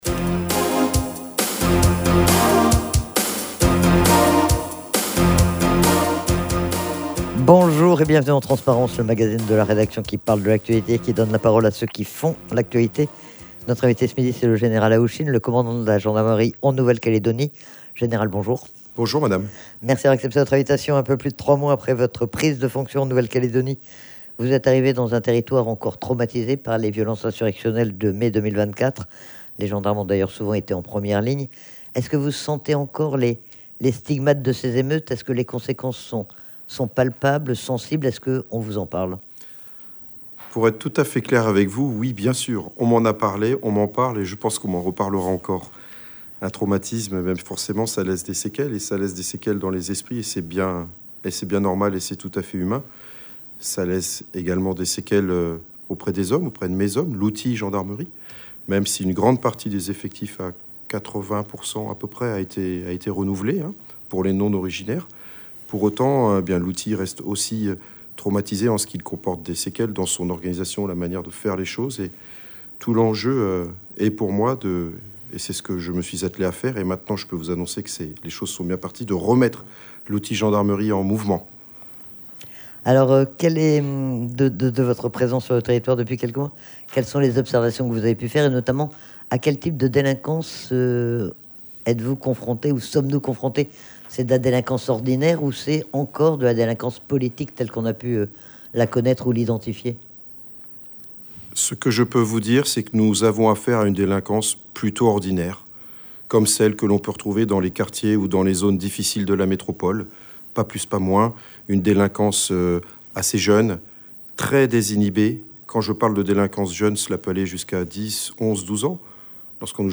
C'est le général Haouchine qui était l'invité